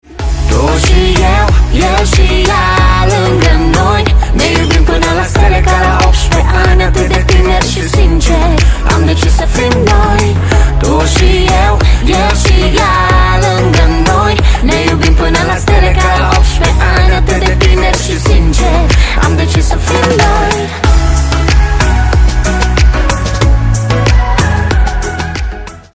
• Качество: 128, Stereo
поп
дуэт
мужской и женский вокал